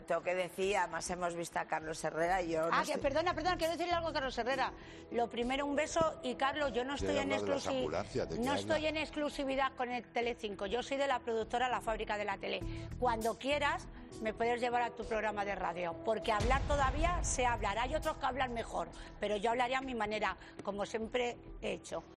Belén Esteban respondió a la oferta de trabajo de Carlos Herrera. La princesa del pueblo aprovechó su presencia en el programa "Sálvame diario" para opinar sobre las palabras que el comunicador le dedicó tras su famosa bronca con Jorge Javier Vázquez por la gestión del Gobierno en la crisis del coronavirus.